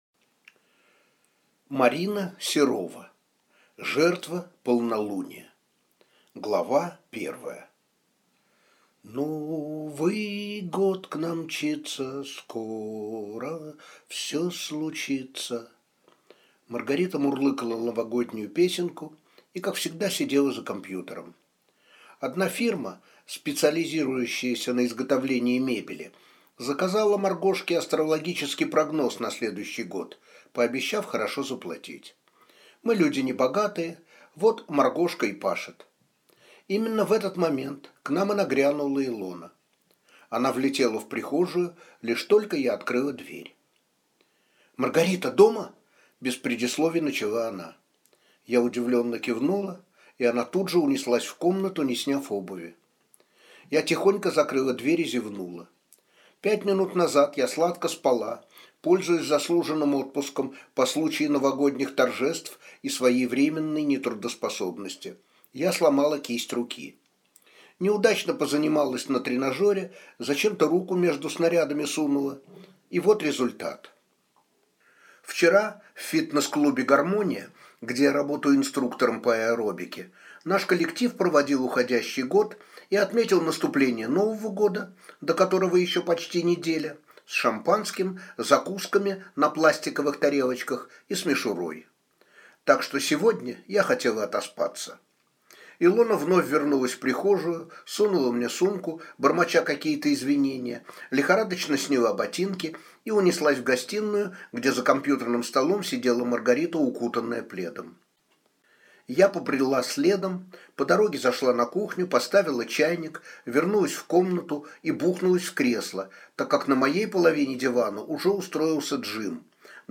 Аудиокнига Жертва полнолуния | Библиотека аудиокниг